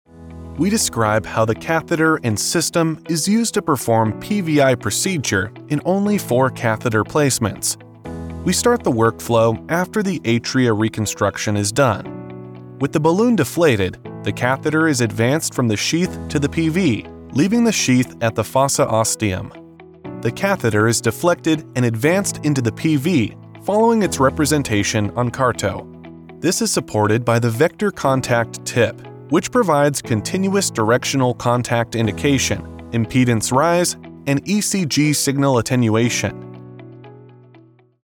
Male
Medical Narration
Words that describe my voice are young voice over, american voice over, male voice over.